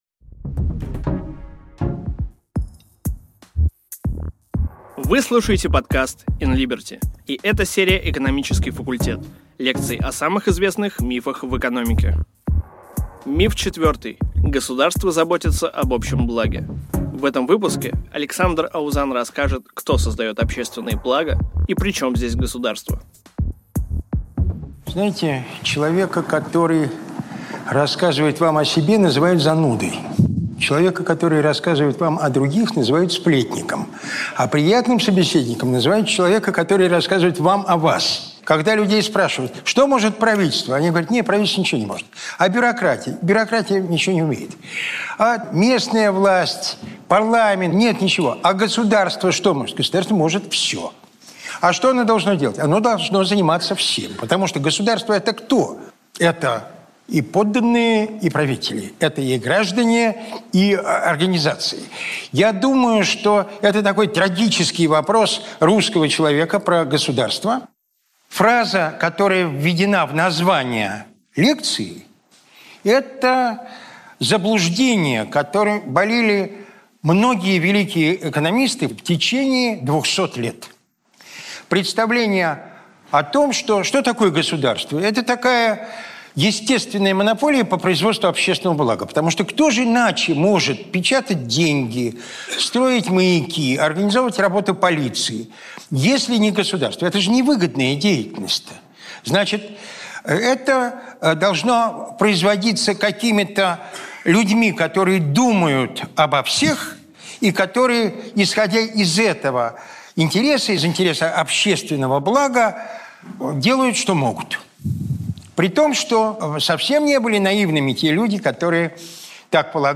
Аудиокнига Государство заботится об общем благе?